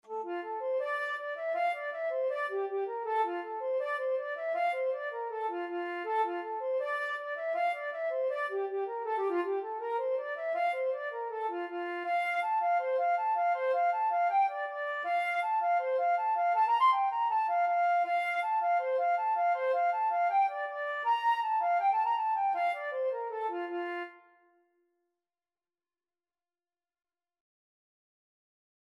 Flute version
4/4 (View more 4/4 Music)
F5-C7
Flute  (View more Intermediate Flute Music)
Traditional (View more Traditional Flute Music)